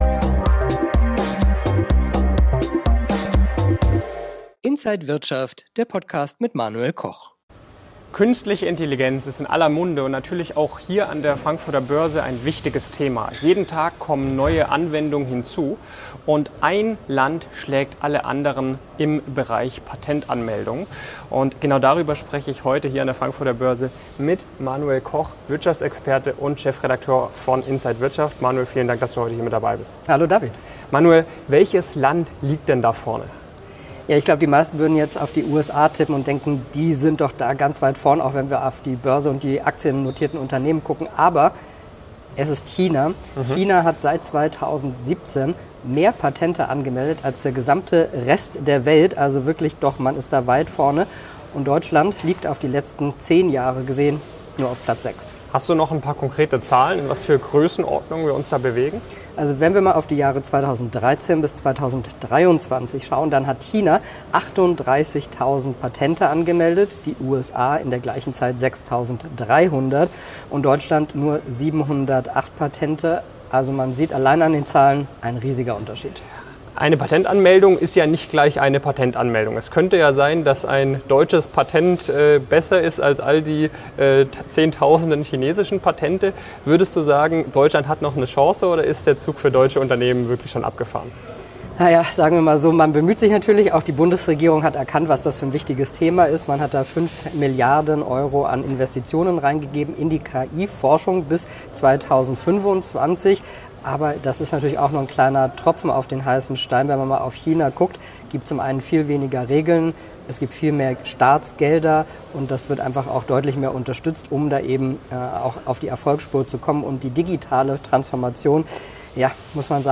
Alle Details im Interview von
an der Frankfurter Börse